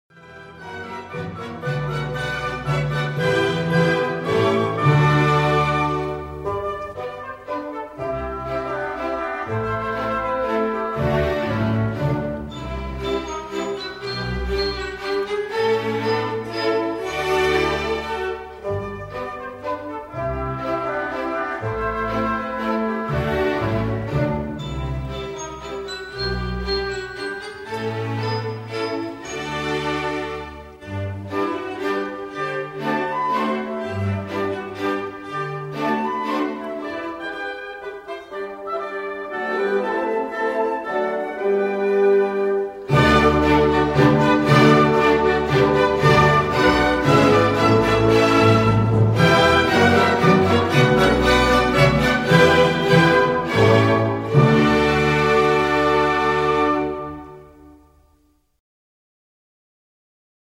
Cantata for:
Minuet for symphony orchestra